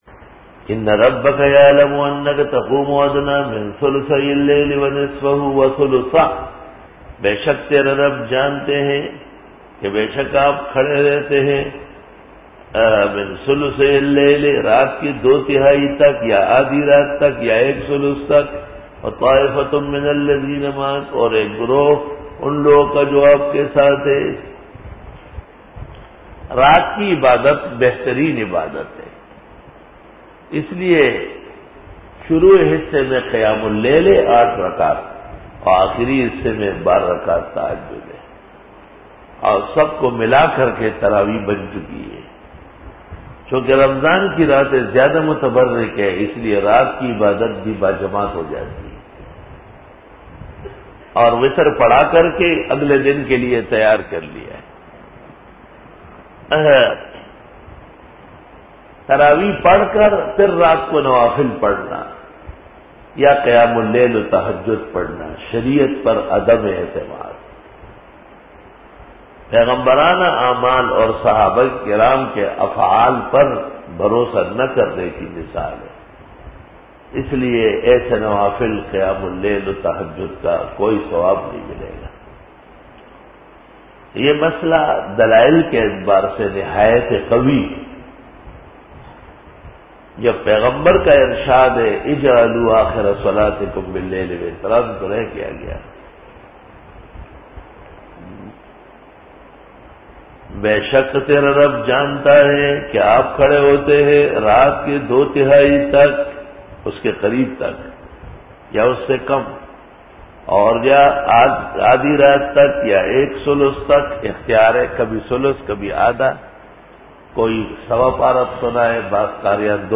Dora-e-Tafseer 2008